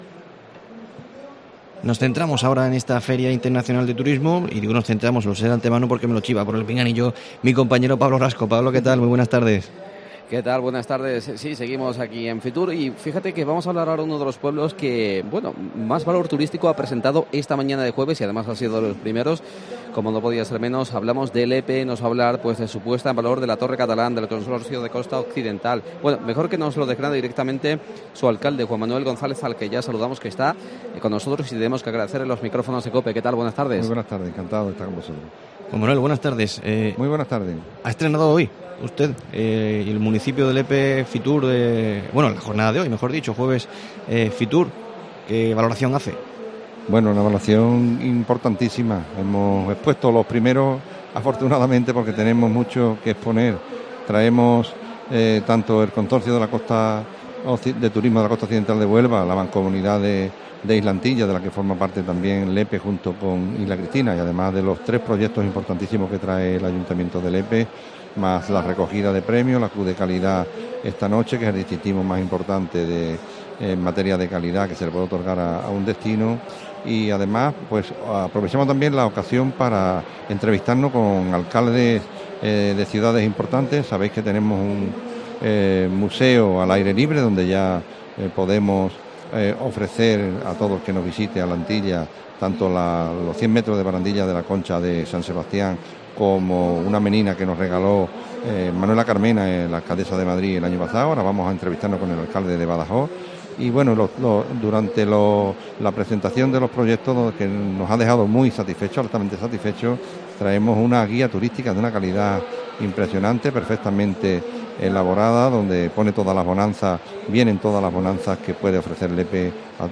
Juan Manuel González, alcalde de Lepe, atiende a COPE Huelva desde la Feria Internacional de Turismo para mostrar las fortalezas turísticas del municipio.